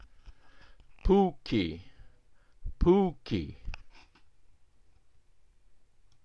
Polish Words -- Baritone Voice
póki (POO - kee)
NOTE: The "accented" /Ó/ is a different vowel from the "unmodified" /O/ and is pronounced like the [OO] in "spook".